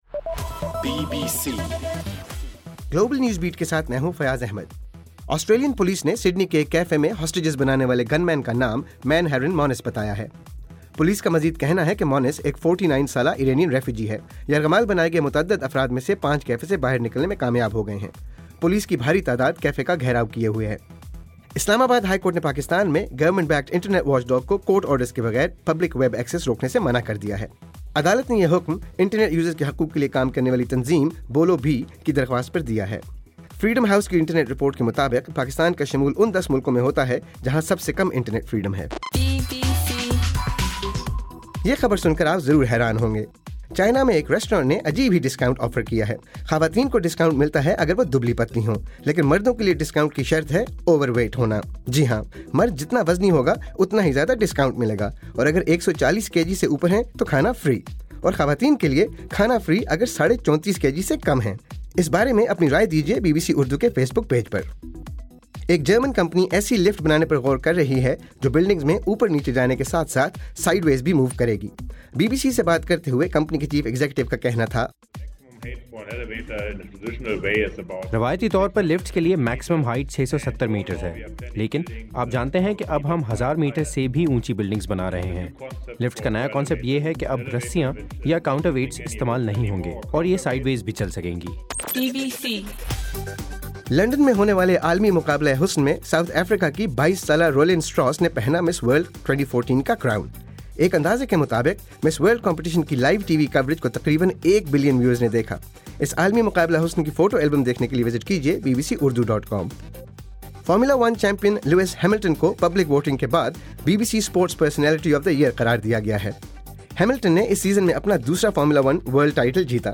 دسمبر 13: رات 9 بجے کا گلوبل نیوز بیٹ بُلیٹن